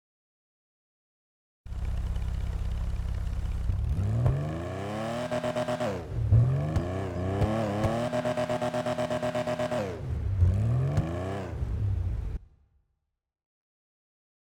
Vehicle haves custom audio file: